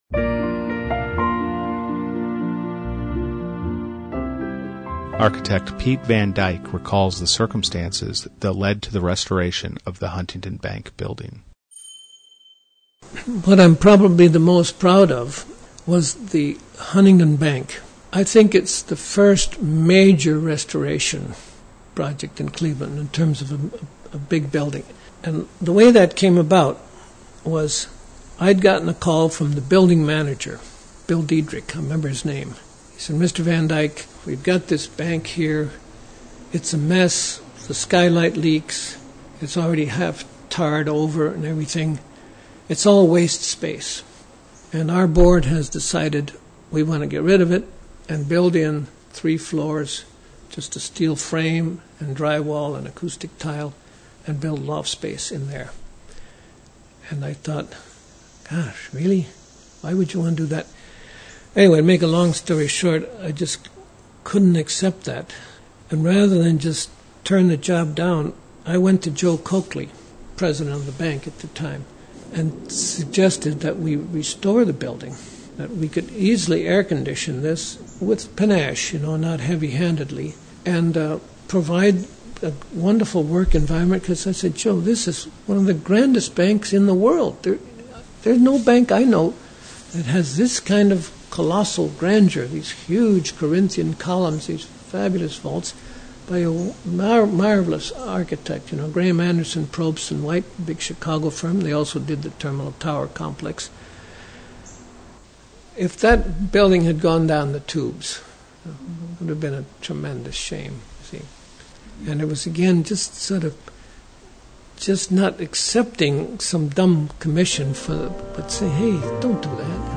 Cleveland Regional Oral History Collection